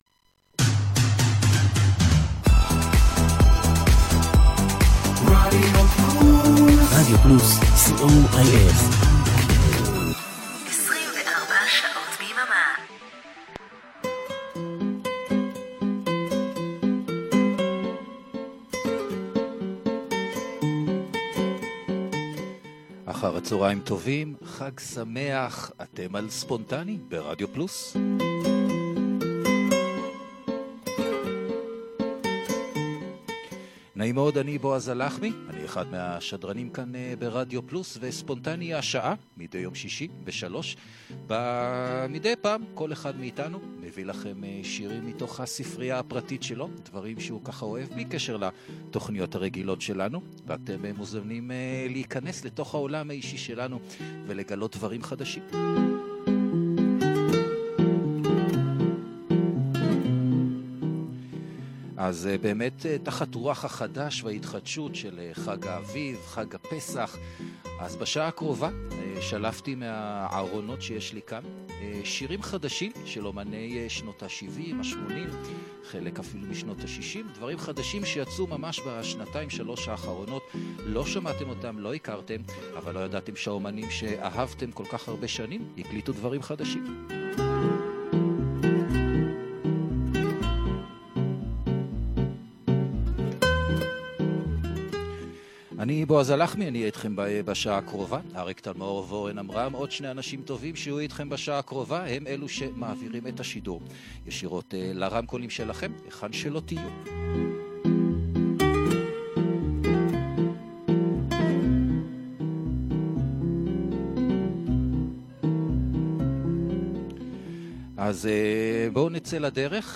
ספונטני ברדיו פלוס כל שישי 15:00 שדרני רדיו פלוס מגישים שעה של מוזיקה שהם אוהבים להאזנה